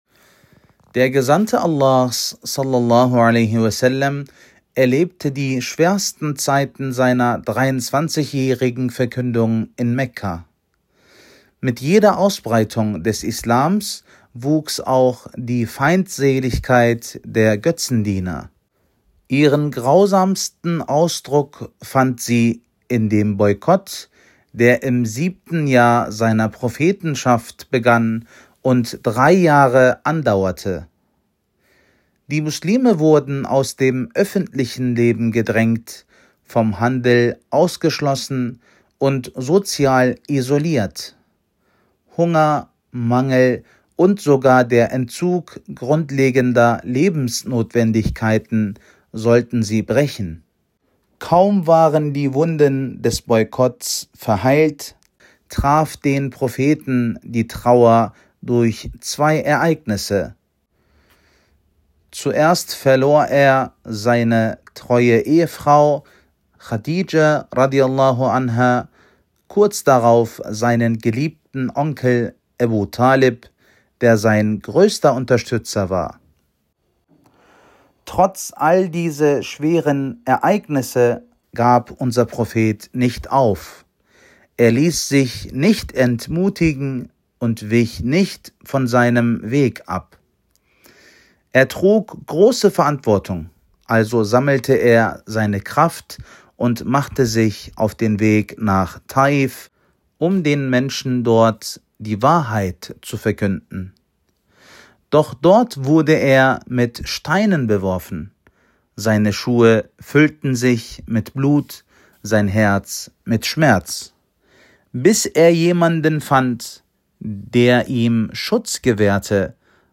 Freitagspredigt